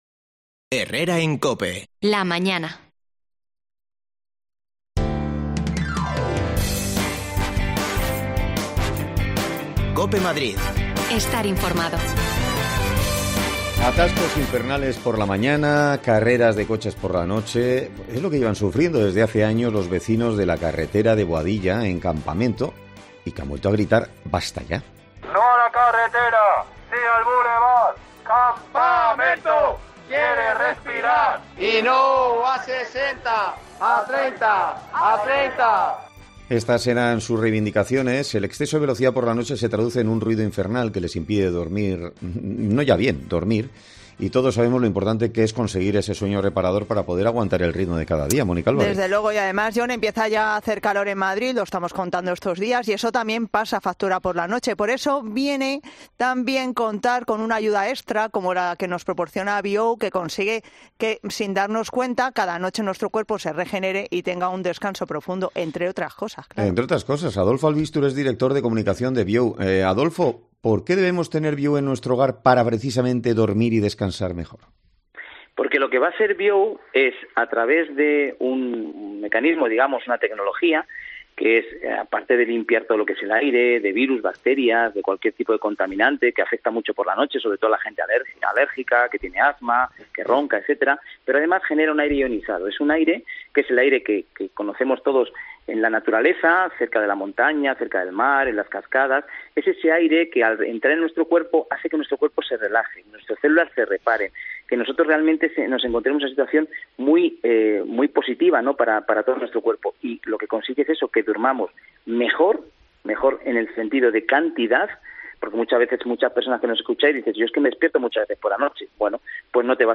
Hablamos con los vecinos afectados